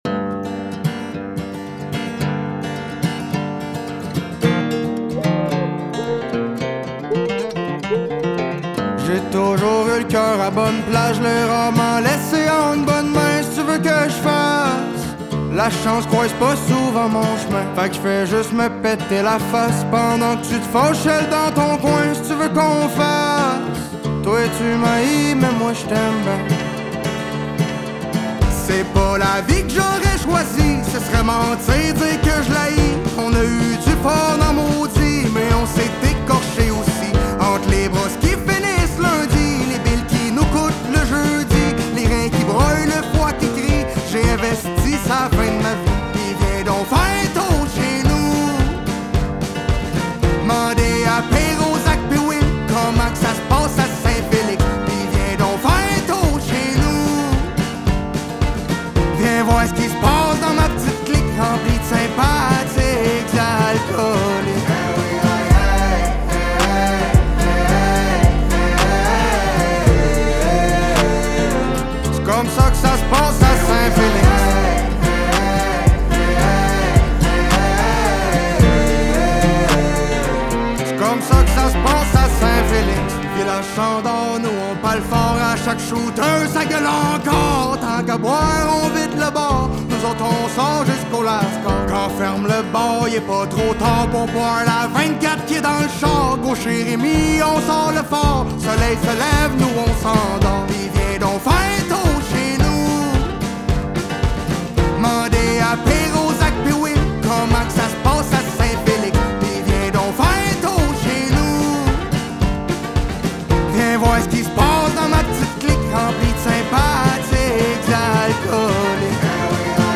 Genre : Folk-Pop